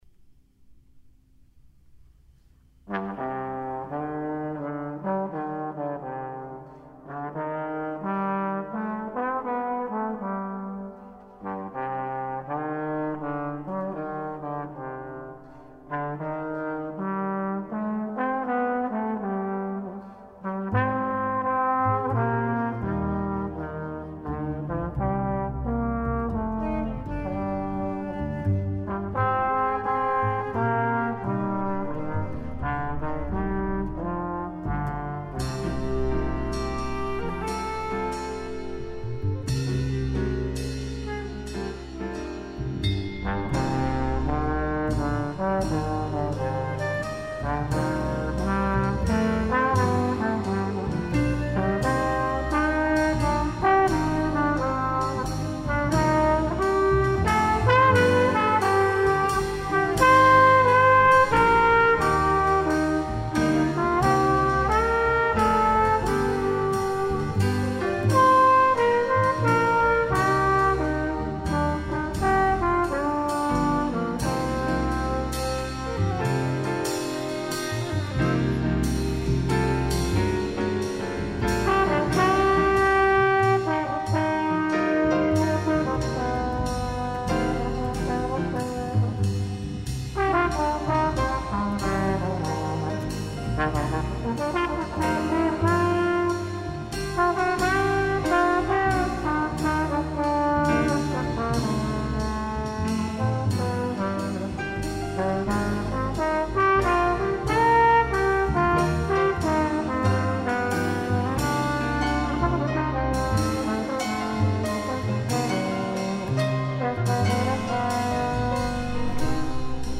jazzig nattvardsmusik
”Anslaget är lätt, tonen ljus